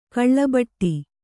♪ kaḷḷabaṭṭi